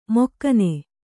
♪ mokkane